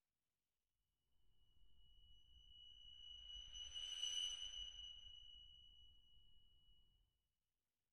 Bruh Sound Effect #1
Perfect for booming, bruh, dramatic.
booming bruh dramatic epic heroic meme sinister terrifying sound effect free sound royalty free Sound Effects